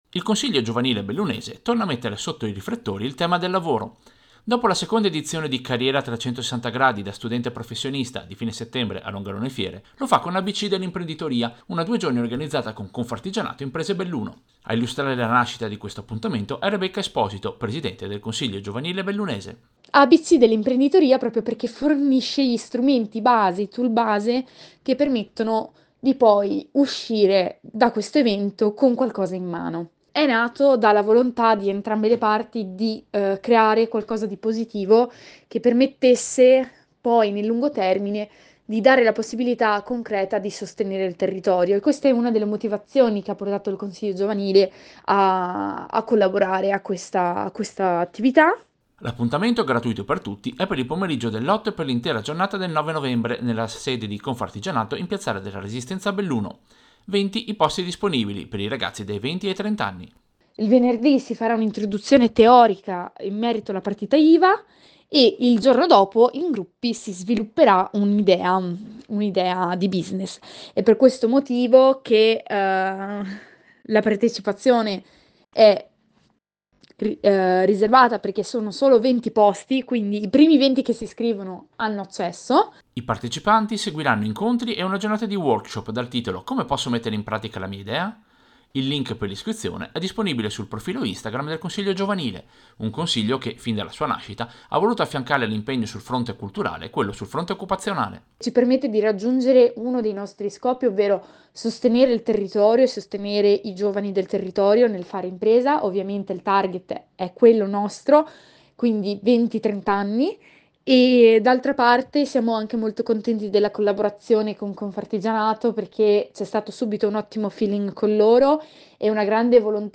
Servizio-Consiglio-giovanile-Confartigianato.mp3